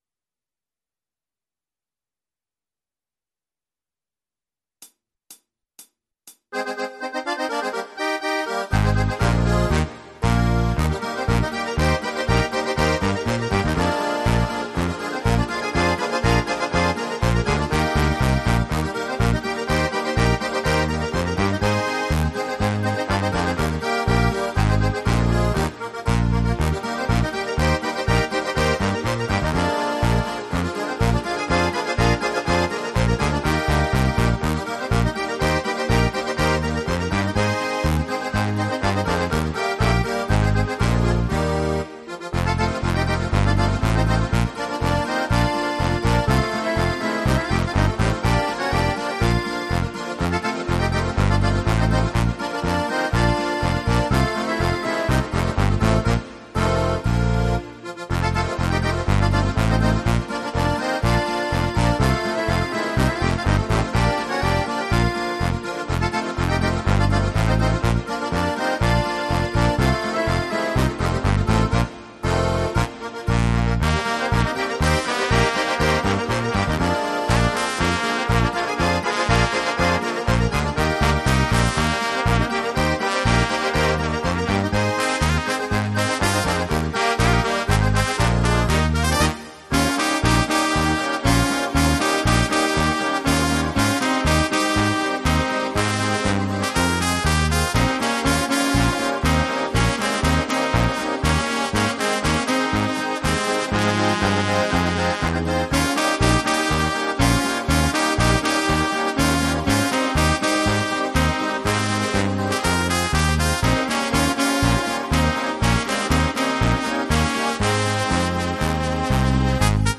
la version instrumentale multipistes